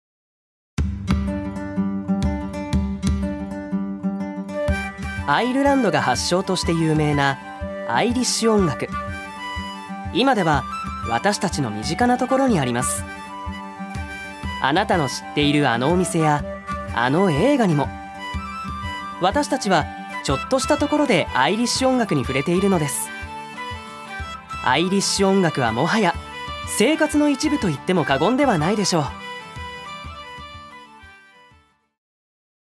所属：男性タレント
音声サンプル
ナレーション１